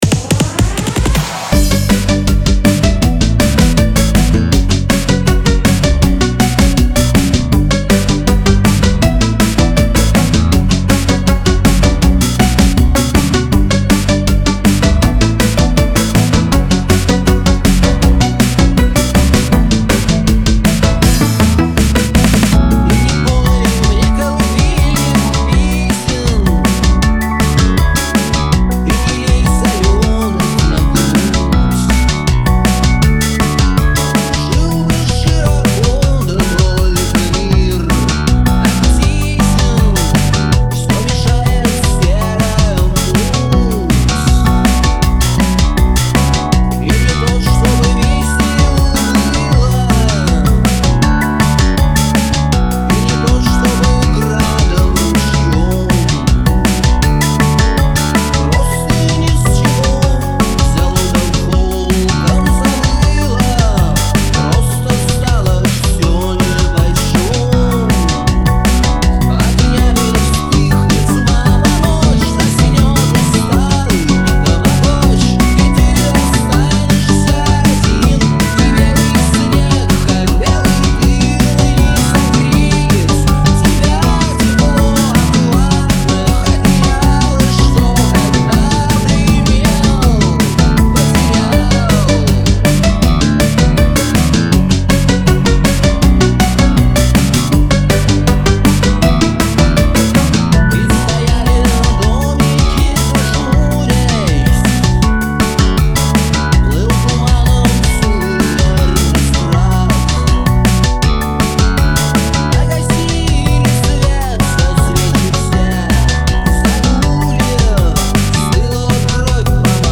Это рабочая версия, недоделанная.